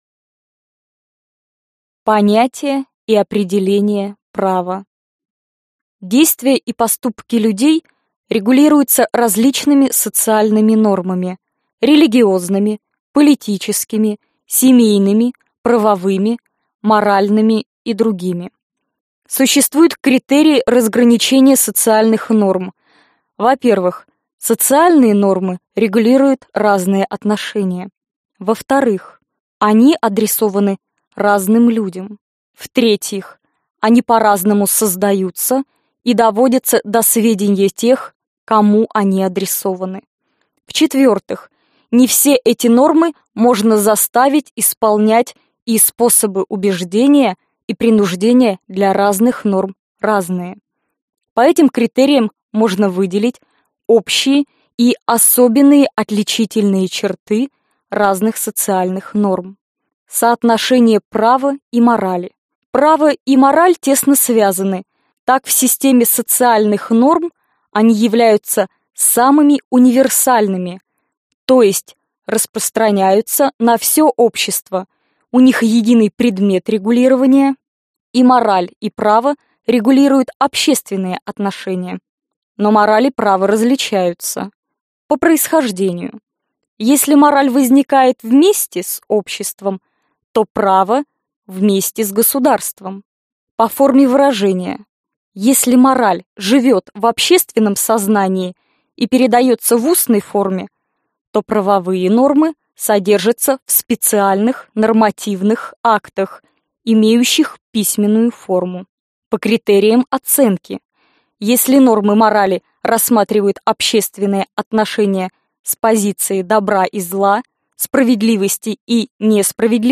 Аудиокнига Лекции по праву | Библиотека аудиокниг